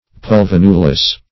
Search Result for " pulvinulus" : The Collaborative International Dictionary of English v.0.48: Pulvinulus \Pul*vin"u*lus\, n.; pl.